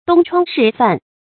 東窗事犯 注音： ㄉㄨㄙ ㄔㄨㄤ ㄕㄧˋ ㄈㄢˋ 讀音讀法： 意思解釋： 見「東窗事發」。